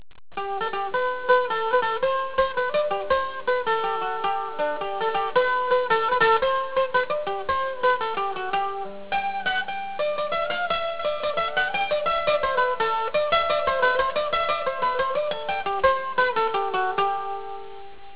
MANDOLIN
Mandolin Sound Clips